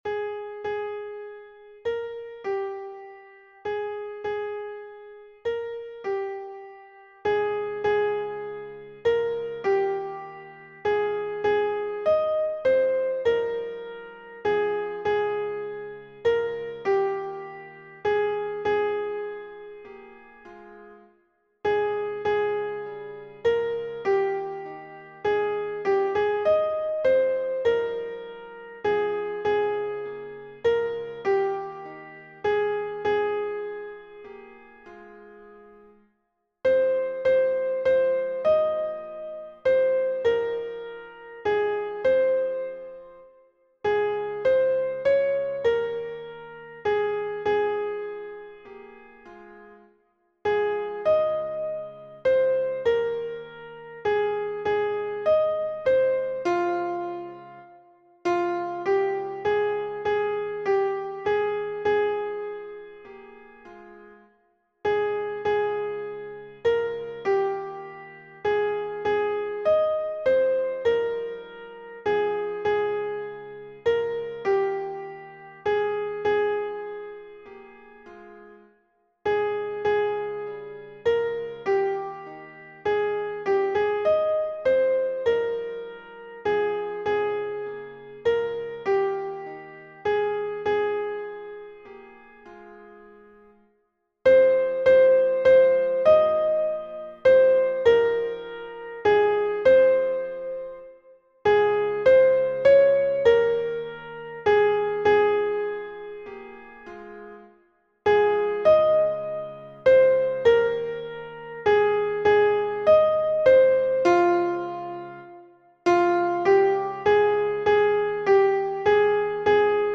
- Œuvre pour chœur à 4 voix mixtes (SATB) + 1 voix soliste
Soprano Version Piano